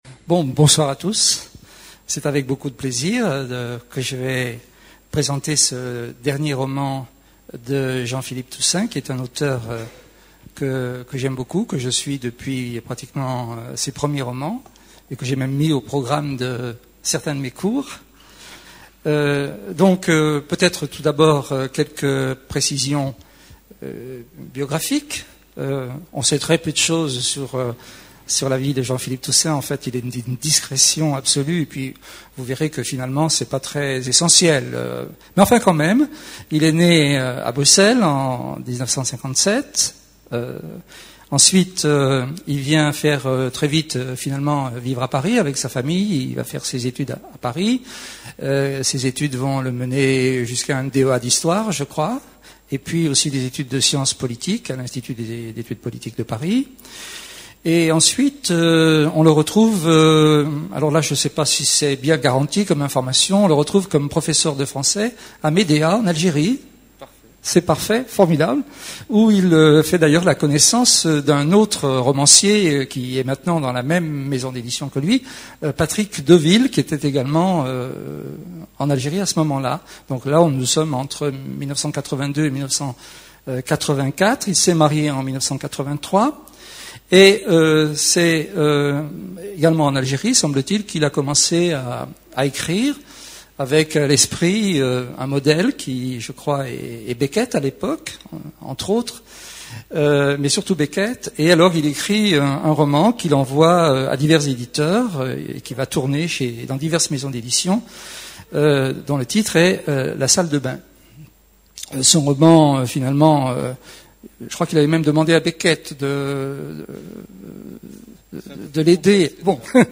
Rencontre avec Jean Philippe Toussaint pour son nouveau roman "La vérité sur Marie"
Toussaint, Philippe. Personne interviewée
Rencontre littéraire